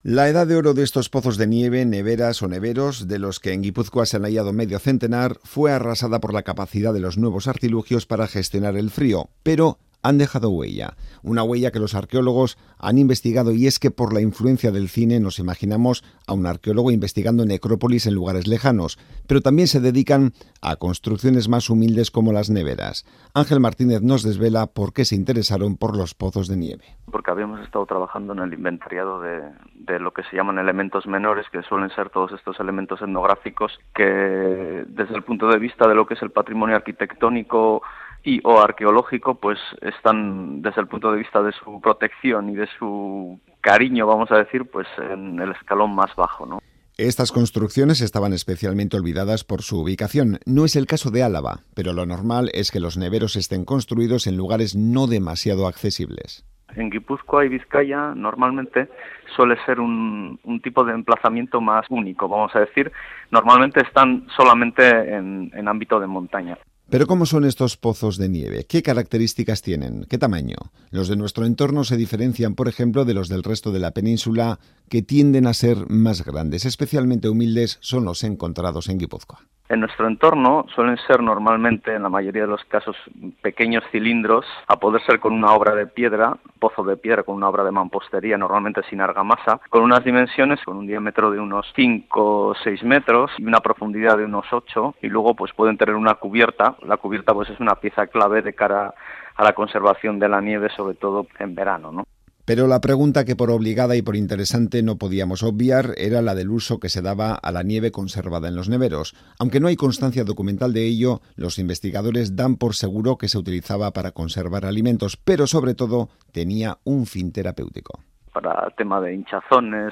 Radio Euskadi REPORTAJES